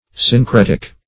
syncretic - definition of syncretic - synonyms, pronunciation, spelling from Free Dictionary
Syncretic \Syn*cret"ic\, a.